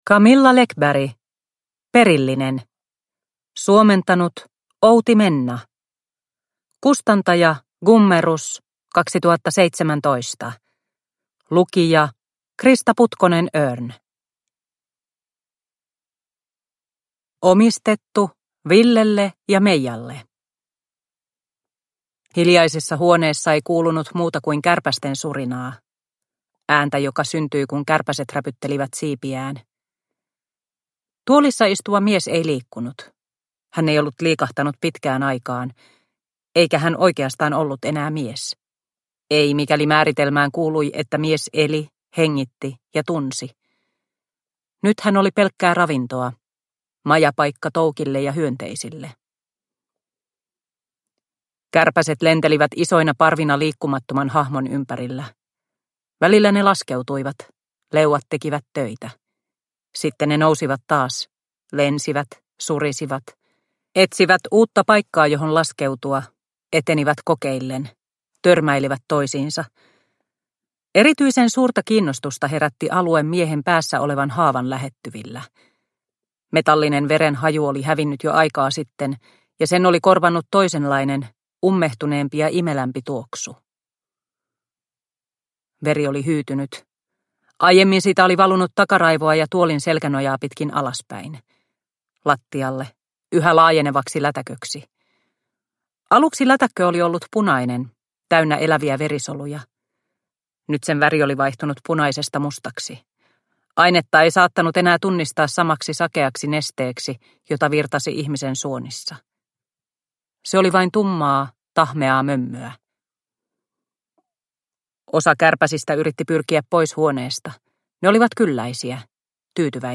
Perillinen – Ljudbok – Laddas ner